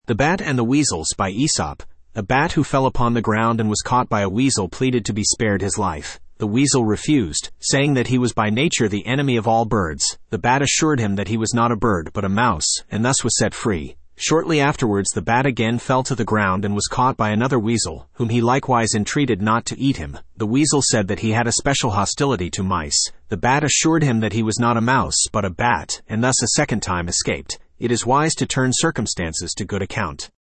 Studio (Male)